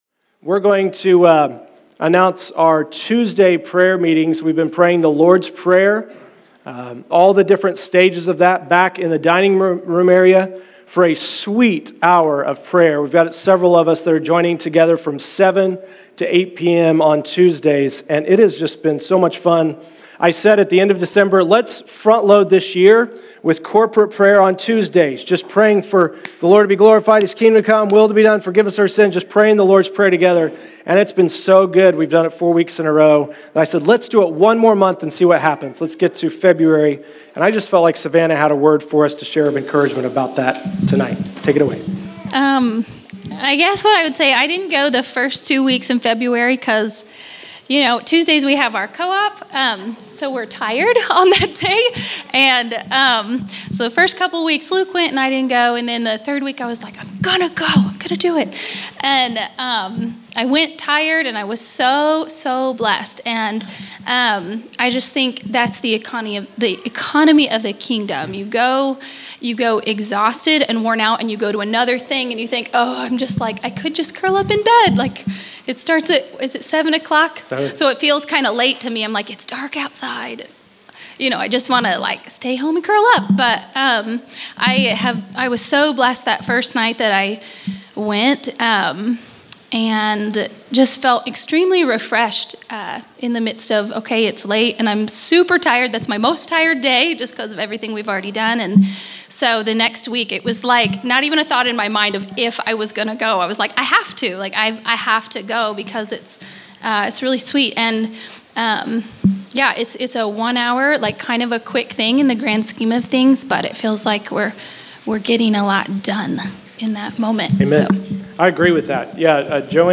Category: Exhortation      |      Location: El Dorado